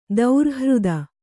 ♪ daurhřda